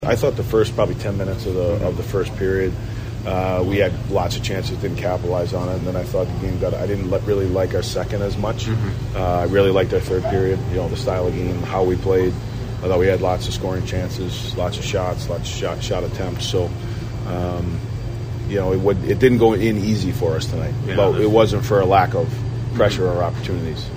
Wild head coach John Hynes recaps the loss and how the Wild put forth a great effort.